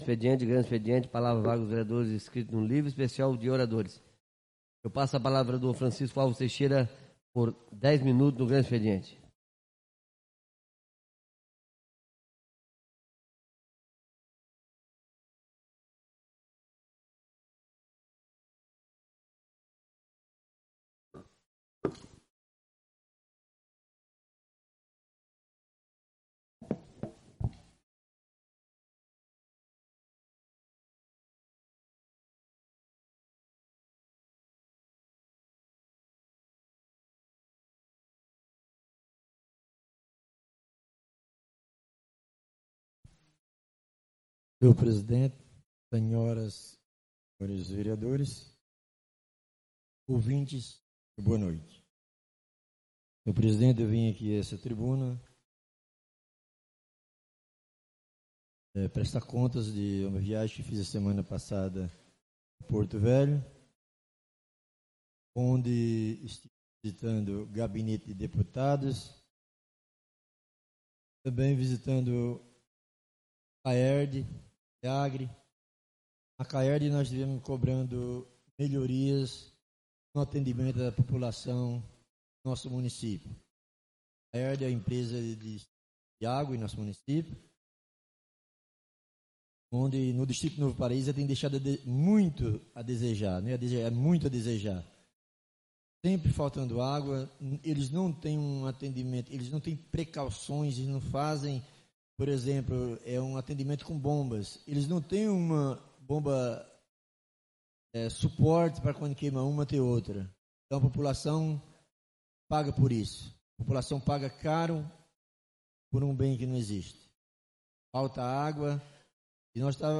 Oradores da Ordem do Dia (29ª Ordinária da 4ª Sessão Legislativa da 6ª Legislatura)